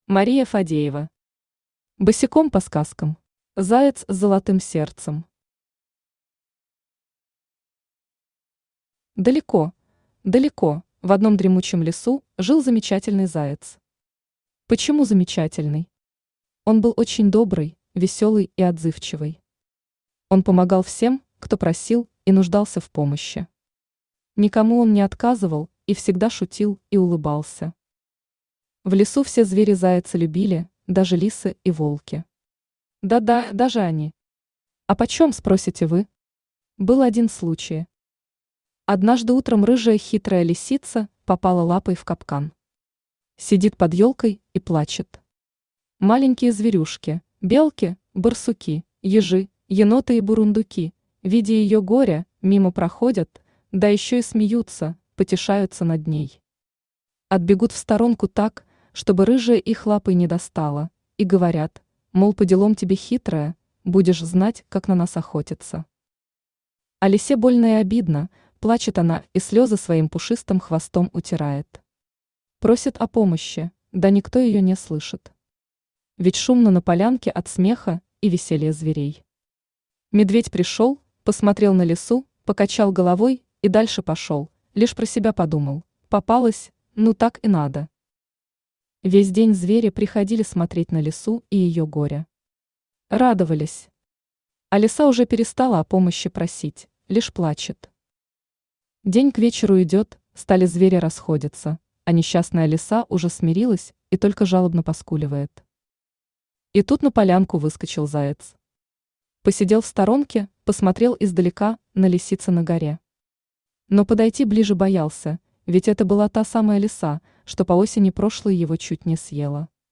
Aудиокнига Босиком по сказкам Автор Мария Юрьевна Фадеева Читает аудиокнигу Авточтец ЛитРес.